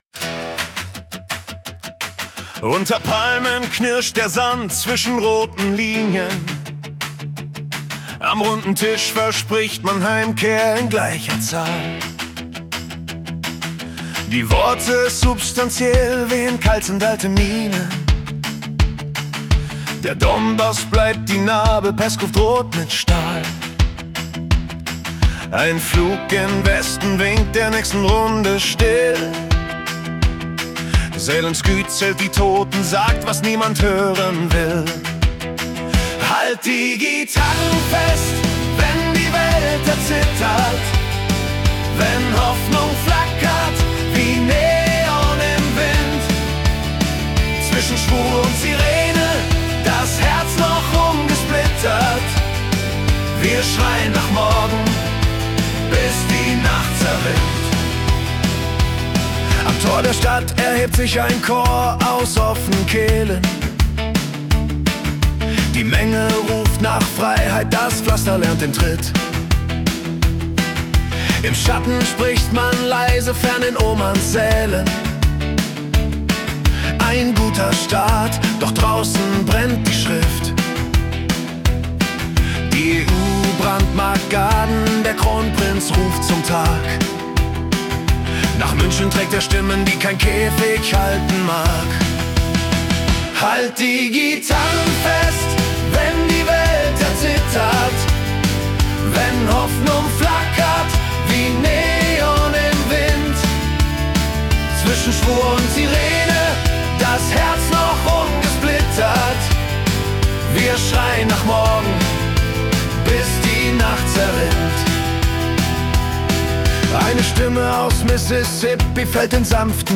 Februar 2026 als Rock-Song interpretiert.